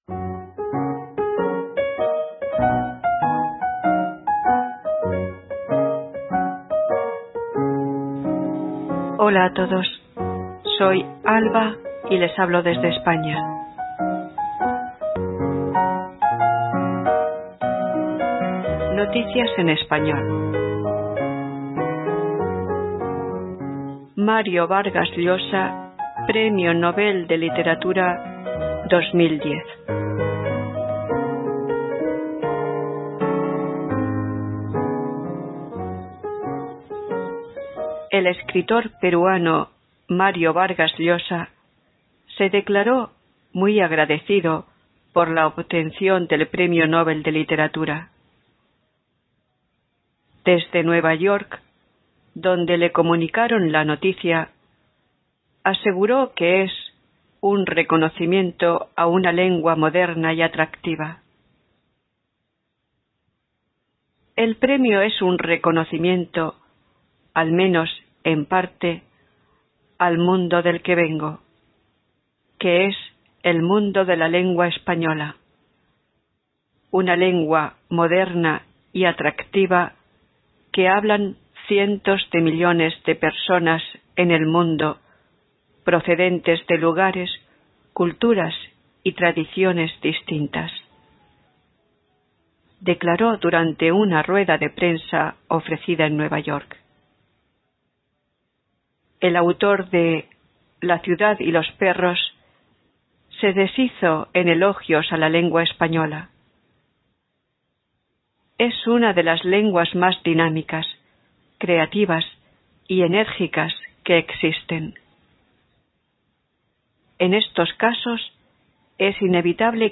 Esta nota se escribió para un periódico, no para una radio.
noticia-no-radio.mp3